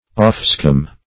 offscum - definition of offscum - synonyms, pronunciation, spelling from Free Dictionary
offscum - definition of offscum - synonyms, pronunciation, spelling from Free Dictionary Search Result for " offscum" : The Collaborative International Dictionary of English v.0.48: Offscum \Off"scum`\, n. [Off + scum.]